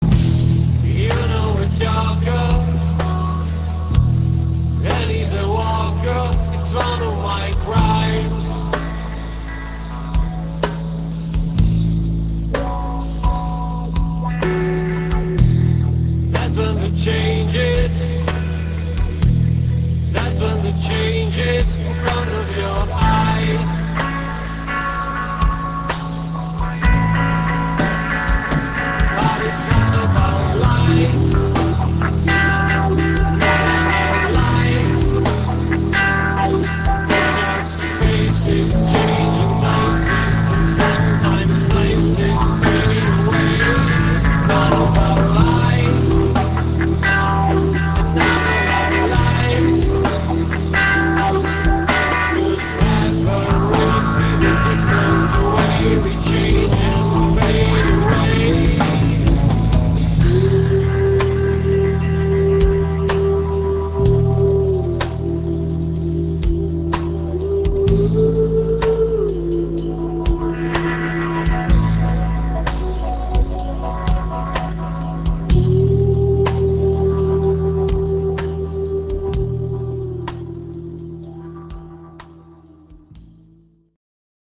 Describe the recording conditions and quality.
168 kB MONO